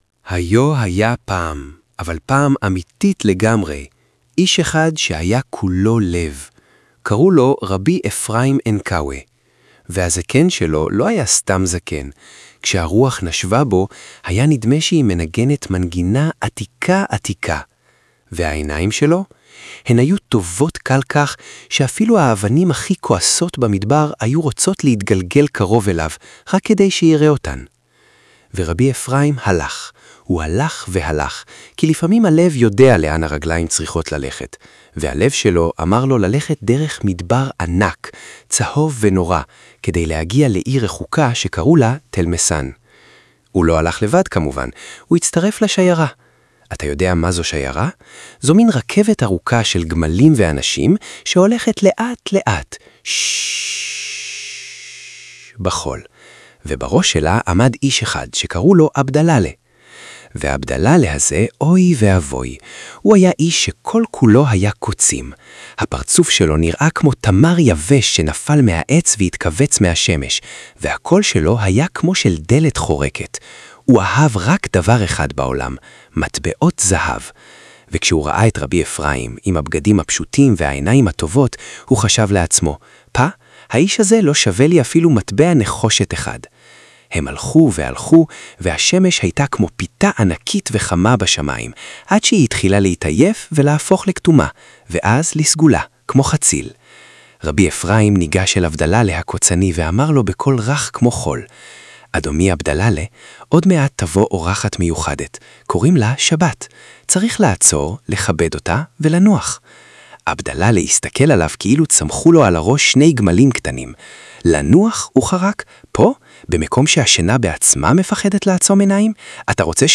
שיתוף | SoundForge AI אתר להפיכת טקסט לדיבור ברמה מקצועית!